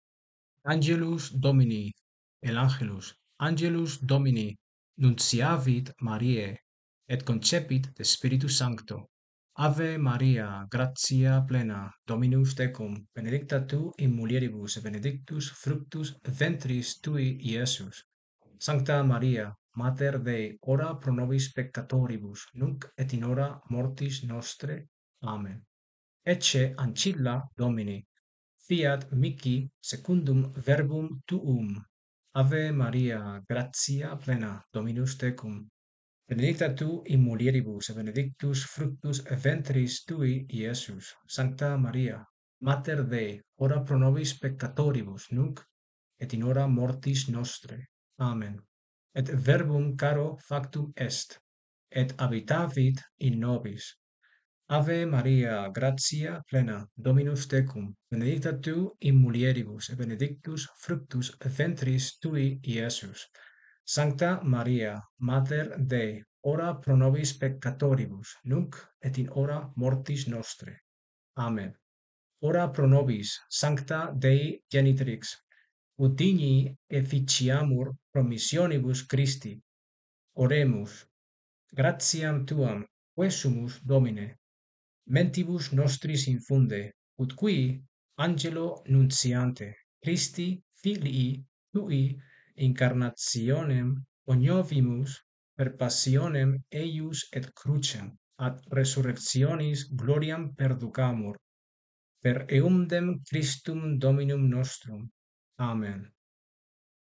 (Descarga el audio de cómo pronunciar el Angelus Domini)